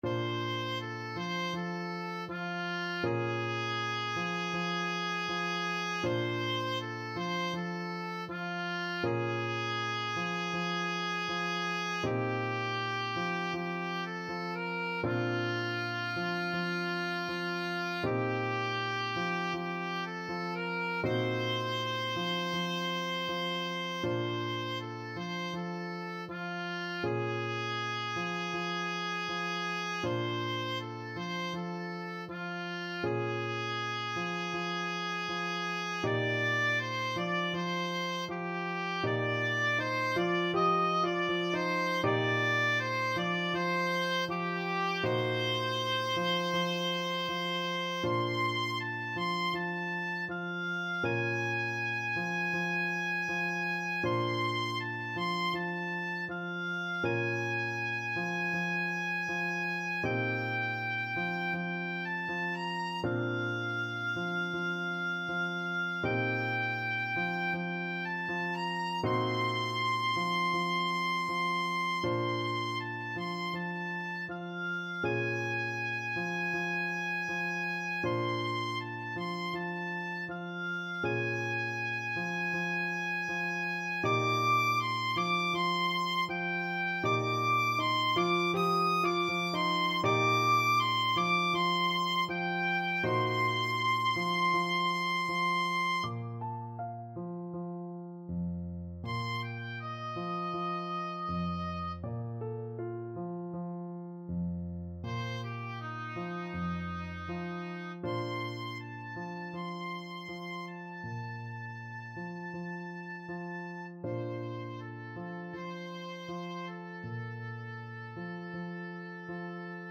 A relaxed melody with a Rumba beat.
Andante